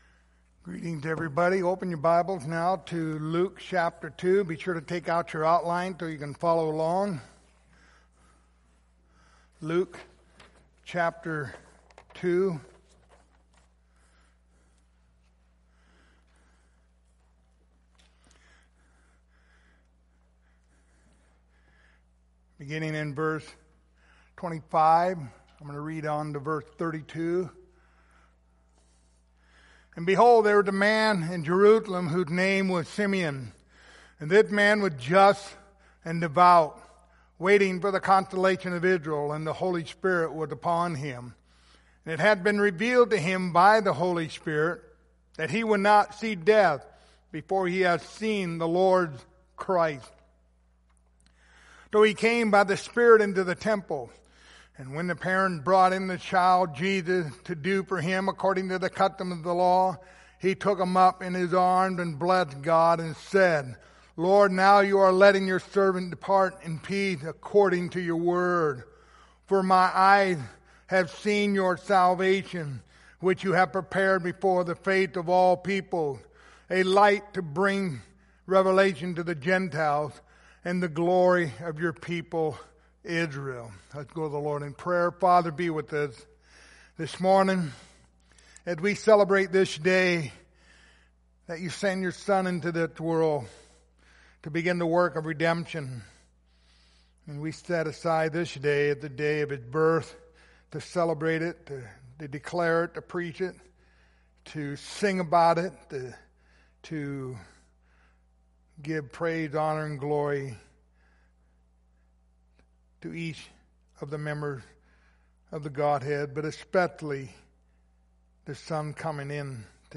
Passage: Luke 2:25-32 Service Type: Sunday Morning Topics